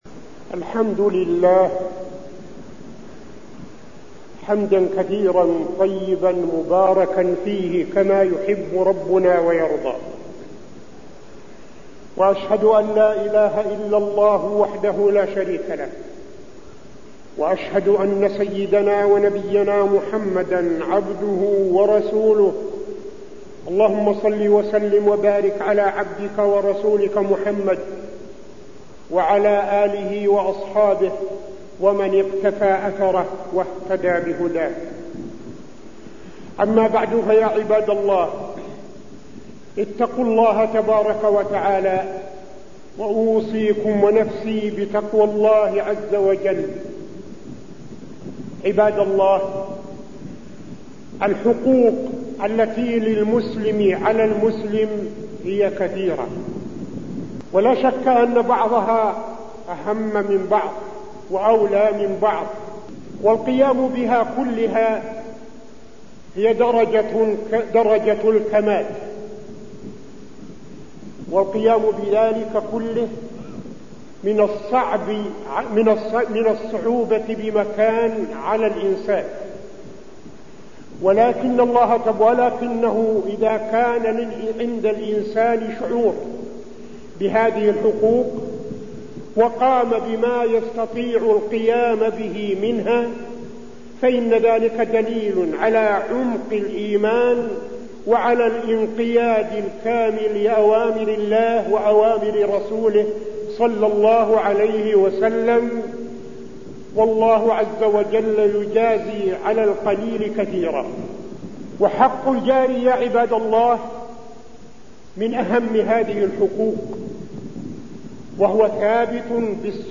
تاريخ النشر ٧ صفر ١٤٠٧ هـ المكان: المسجد النبوي الشيخ: فضيلة الشيخ عبدالعزيز بن صالح فضيلة الشيخ عبدالعزيز بن صالح حق الجار